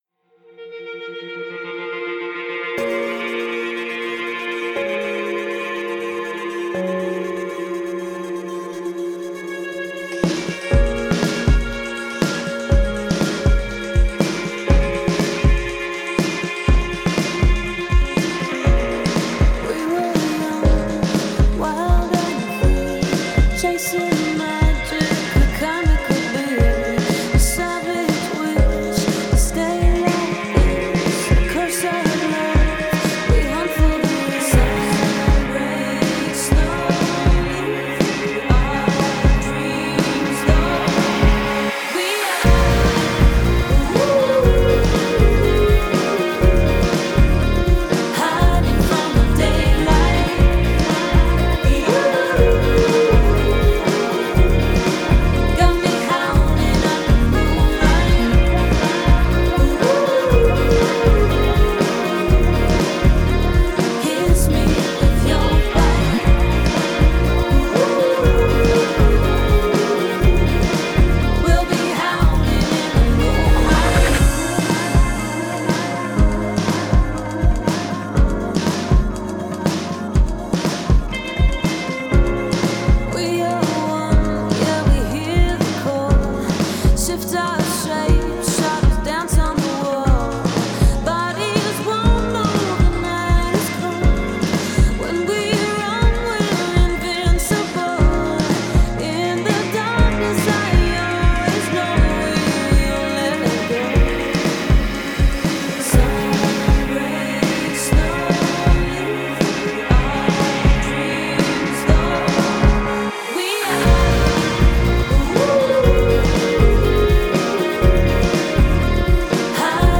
I used ProTools10 with mostly Waves, Flux and Elysia plugs.